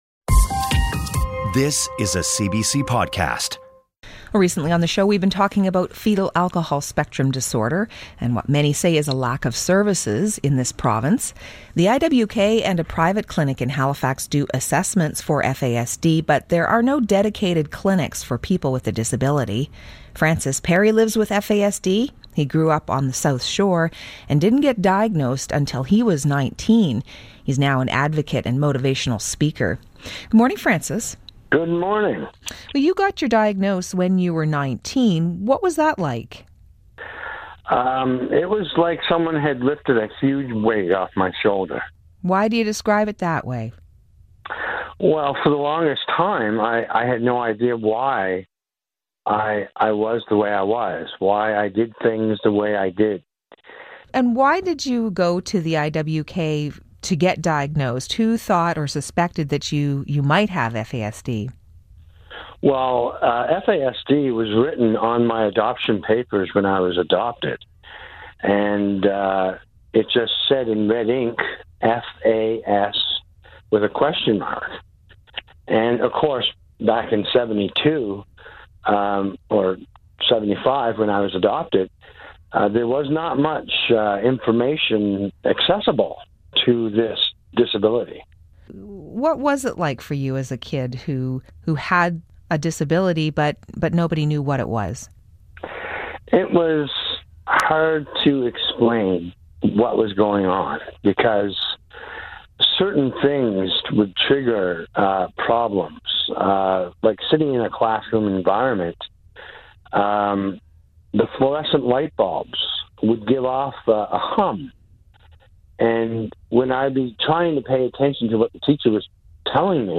CBC Information Morning Radio Interview
CBCInterview.mp3